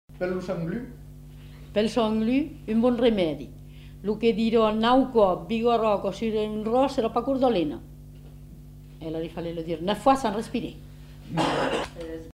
Aire culturelle : Périgord
Genre : forme brève
Type de voix : voix de femme
Production du son : récité
Classification : formulette